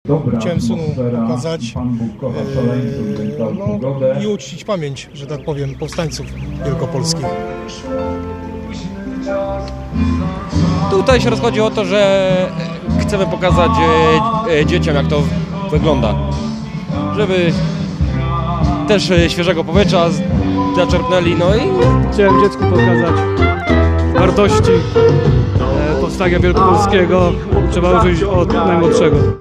Ułani, armata i grochówka na pikniku wojskowym w Poznaniu